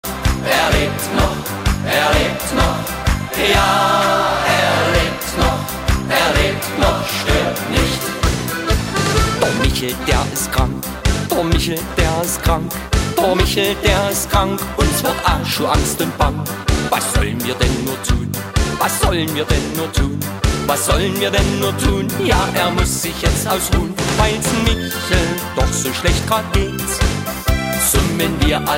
Gattung: Moderner Einzeltitel
Besetzung: Blasorchester
Ein Knaller aus der volkstümlichen Musikszene.
Tonart: B-Dur.